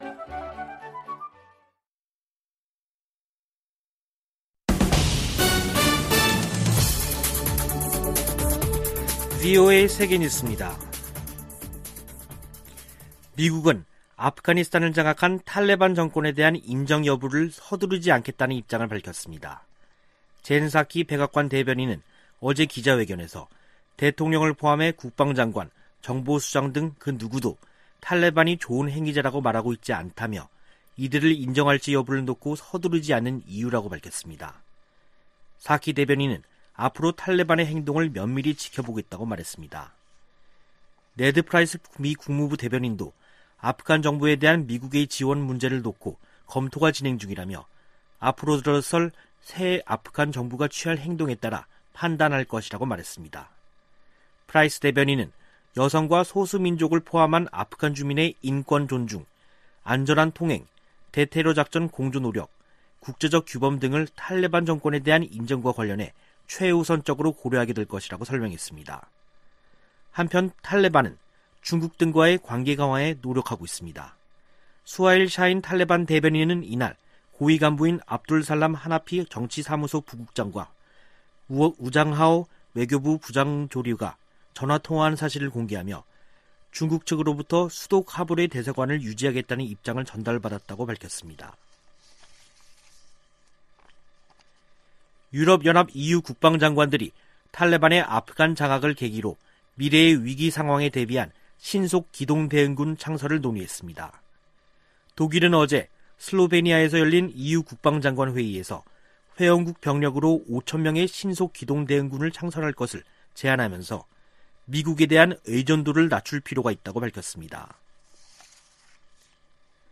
VOA 한국어 간판 뉴스 프로그램 '뉴스 투데이', 2부 방송입니다. 북한이 열병식을 준비하는 것으로 추정되는 모습이 포착됐습니다. 미국의 전직 관리들은 국무부의 북한 여행금지 연장 조치를 지지한다고 밝혔습니다. 주한미군 규모를 현 수준으로 유지해야 한다는 내용이 포함된 미국의 2022회계연도 국방수권법안이 하원 군사위원회에서 의결됐습니다.